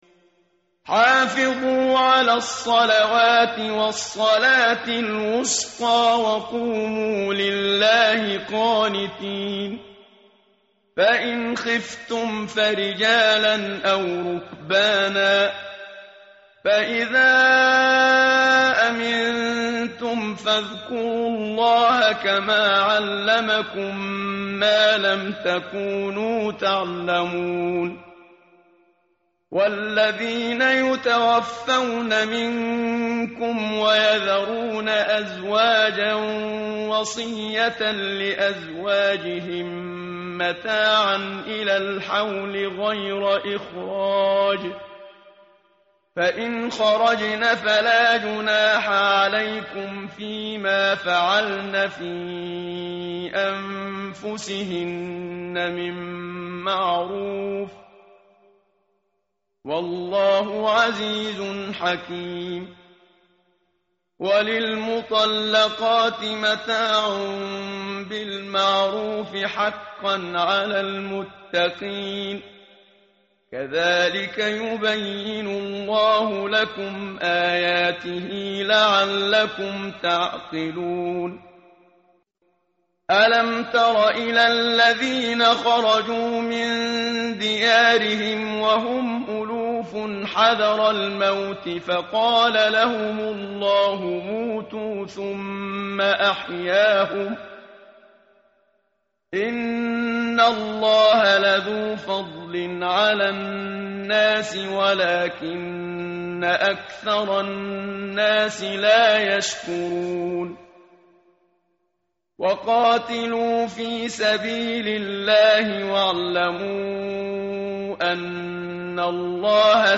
tartil_menshavi_page_039.mp3